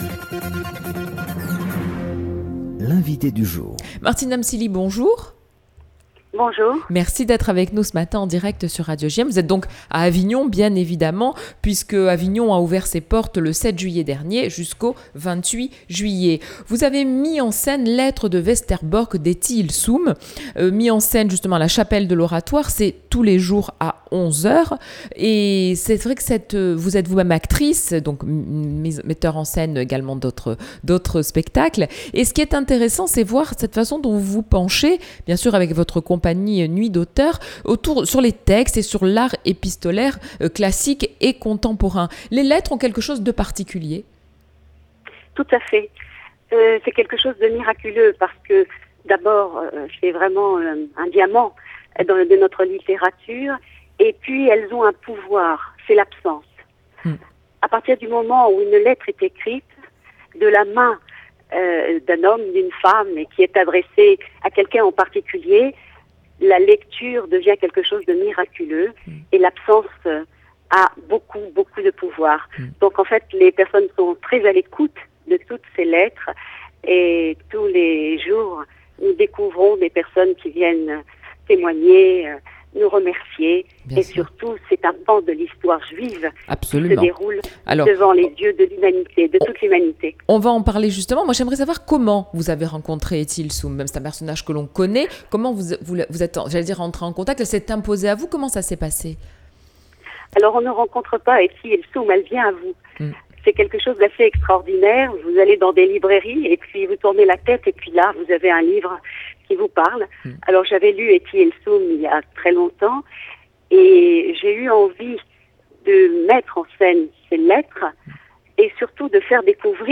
Dernière lettre hommage à Etty HILLESUM